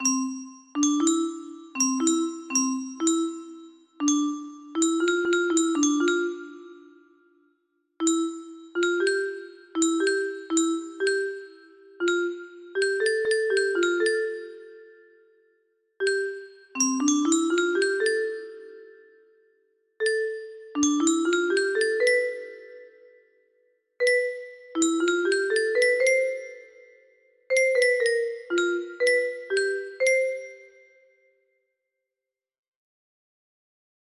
Clone of Unknown Artist - Untitled music box melody
Yay! It looks like this melody can be played offline on a 30 note paper strip music box!